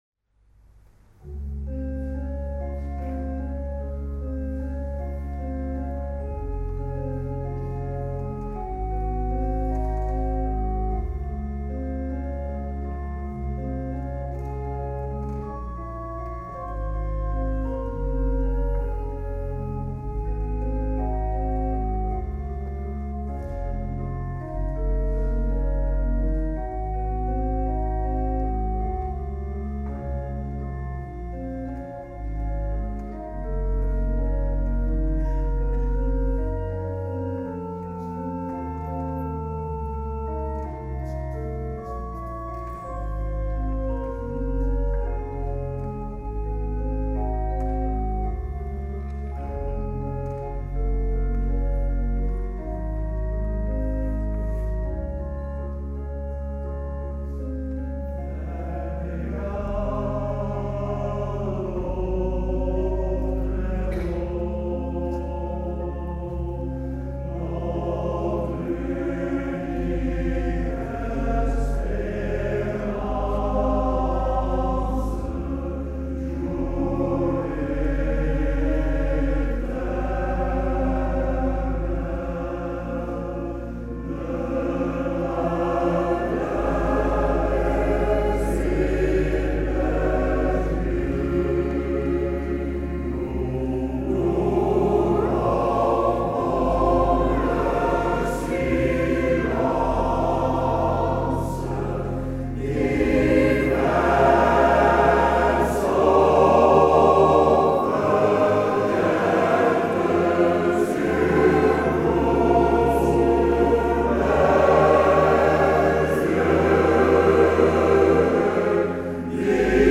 BLIJ RONDEEL SAMEN MET ROSMALENS MANNENKOOR
orgel